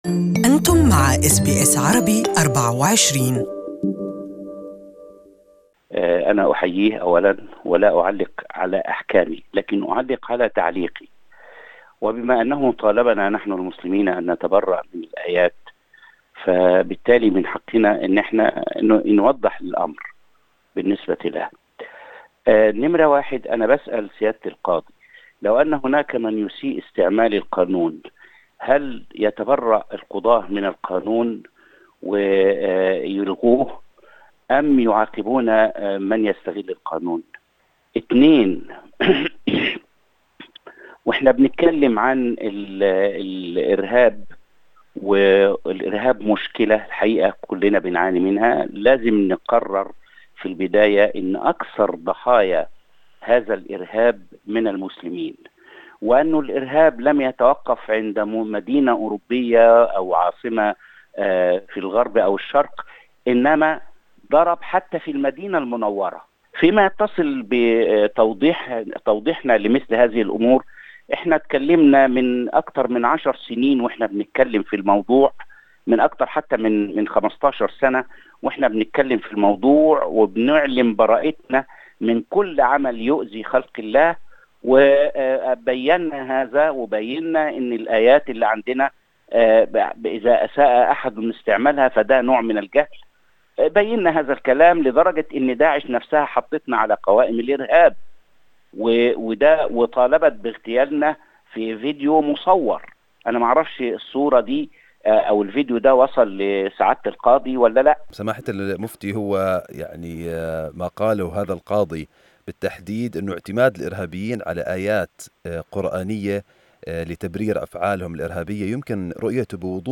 Grand Mufti of Australia Dr Ibrahim Abu Muhammad spoke to SBS Arabic24 to comment on the calls of NSW Supreme Court Judge Das Fagan asking Muslims to denounce verses of Kuran which incite "violence".